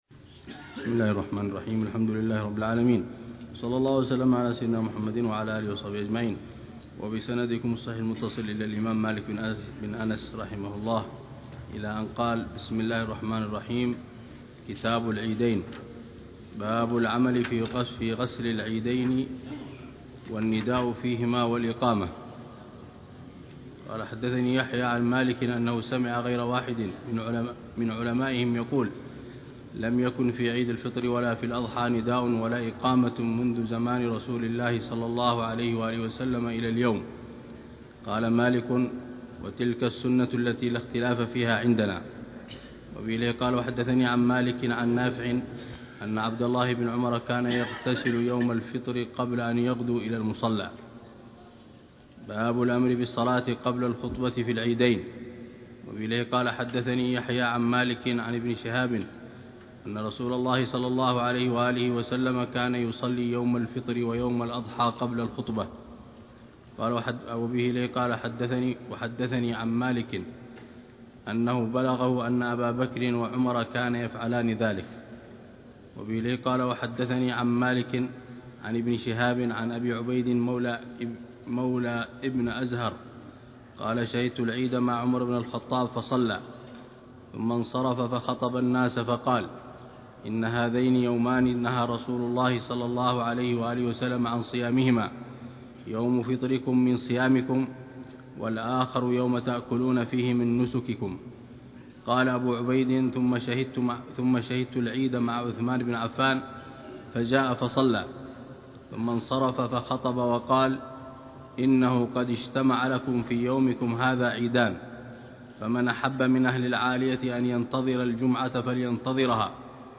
شرح الحبيب العلامة عمر بن محمد بن حفيظ على كتاب الموطأ لإمام دار الهجرة الإمام مالك بن أنس الأصبحي، برواية الإمام يحيى بن يحيى الليثي، كتاب ال